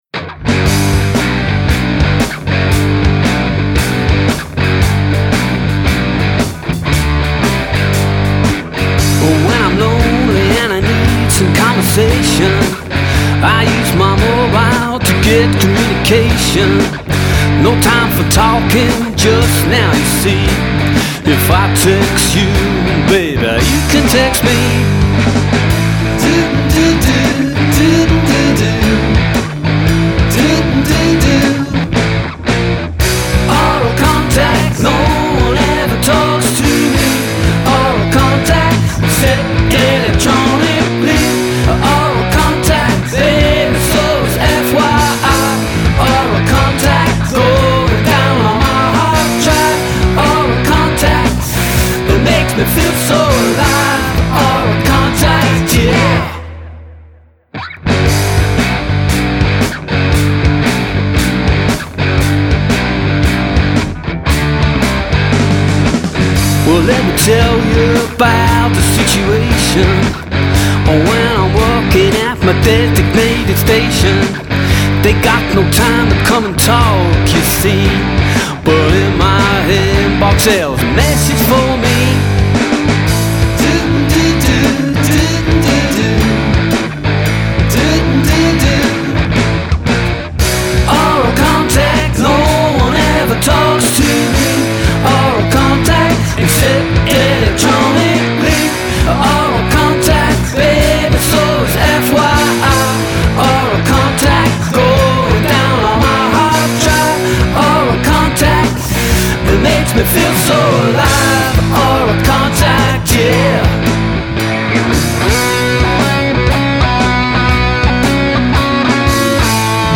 This time I slowed the song down a bit added a couple of chords for the bridge and wrote a lead guitar bit for the middle part.